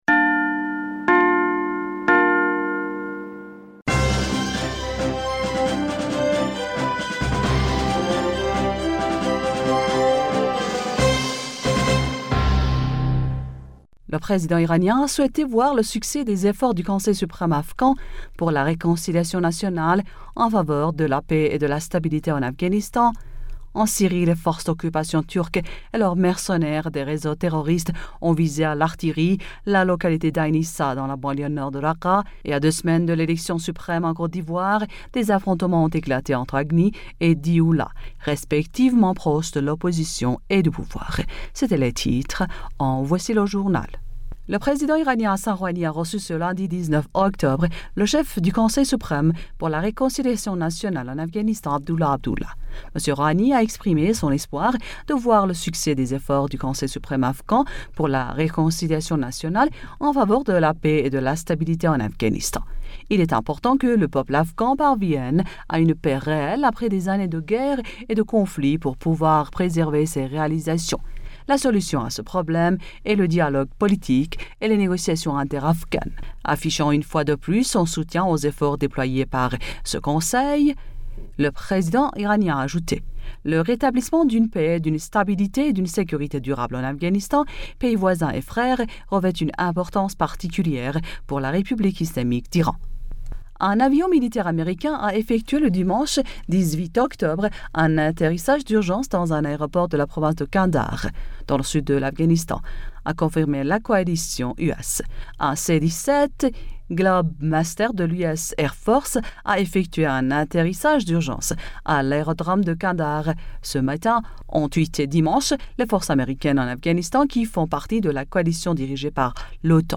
Bulletin d'information du 19 Octobre 2020